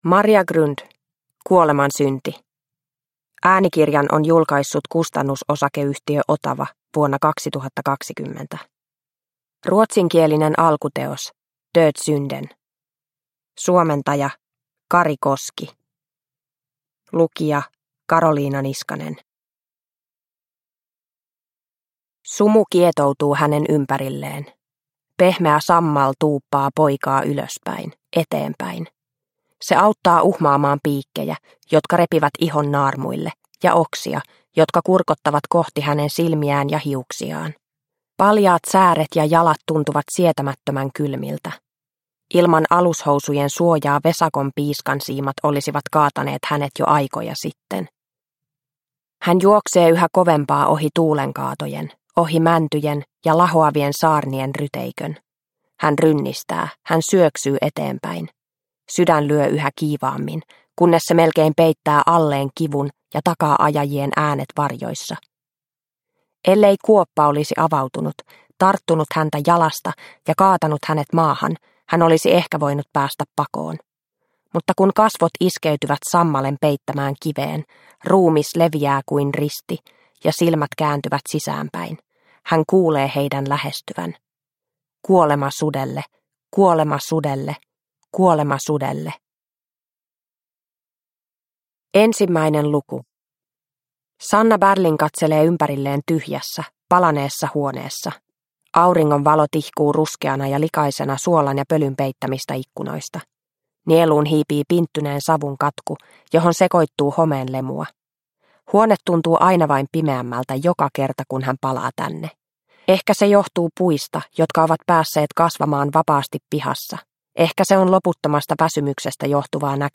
Kuolemansynti – Ljudbok – Laddas ner